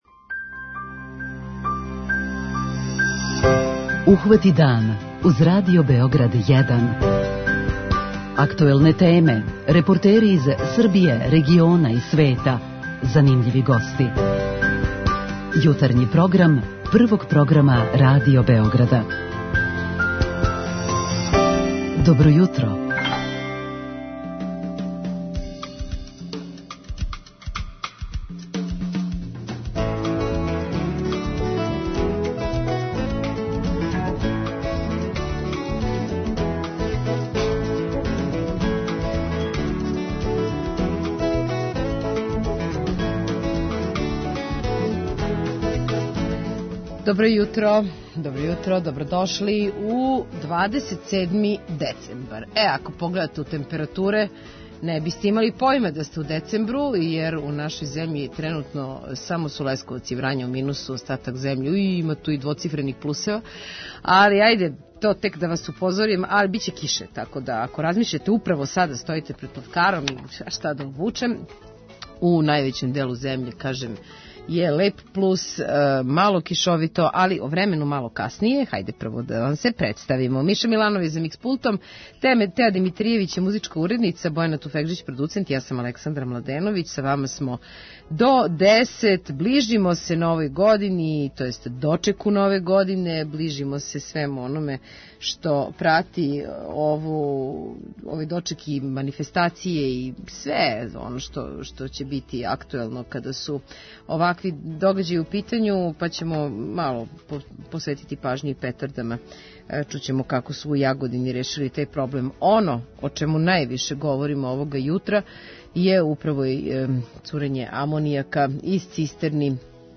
Група аутора Јутарњи програм Радио Београда 1!